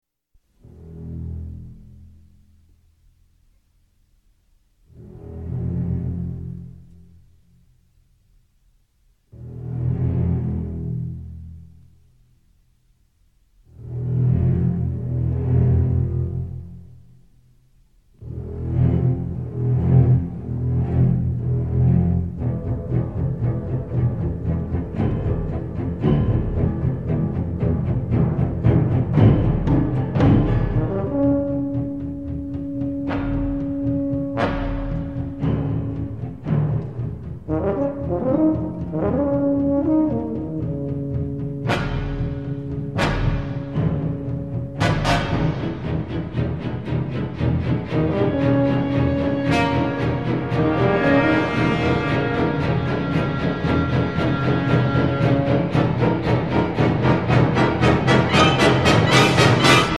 мелодия надвигающейся акулы